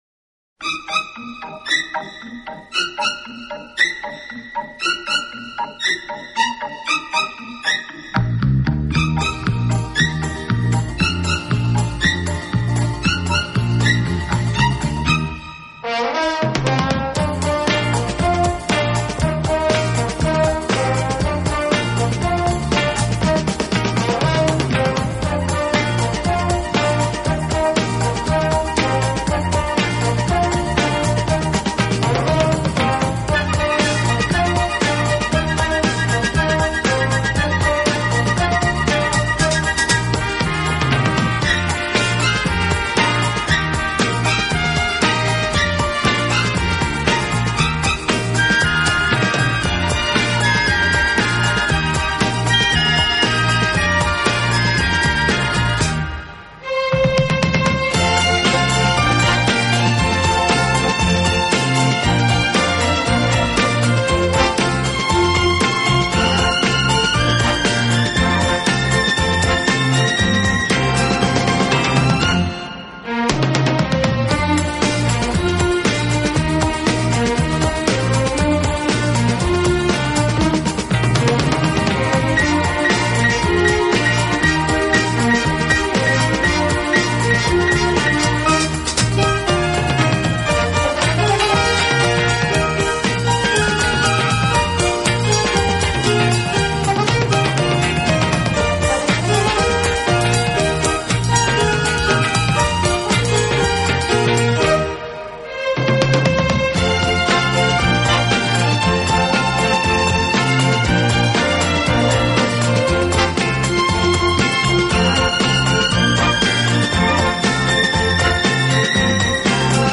【顶级轻音乐】
好处的管乐组合，给人以美不胜收之感。